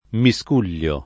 vai all'elenco alfabetico delle voci ingrandisci il carattere 100% rimpicciolisci il carattere stampa invia tramite posta elettronica codividi su Facebook miscuglio [ mi S k 2 l’l’o ] (pop. tosc. mescuglio ) s. m.; pl. -gli